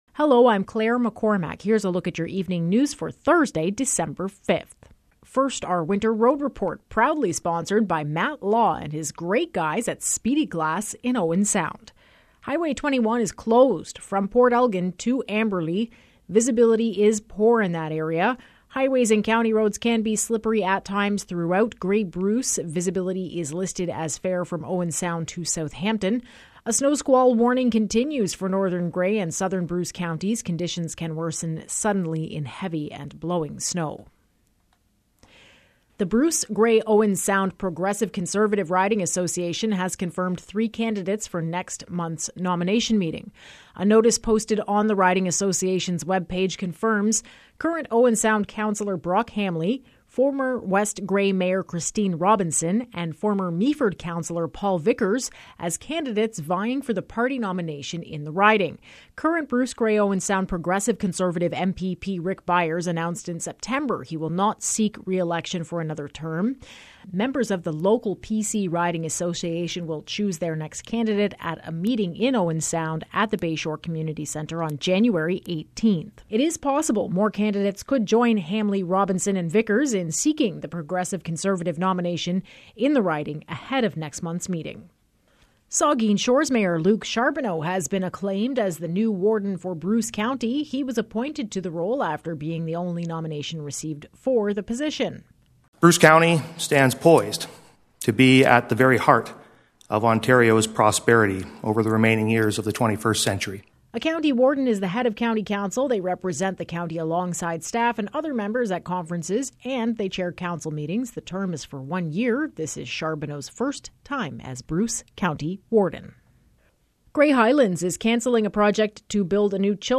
Evening News – Thursday, December 5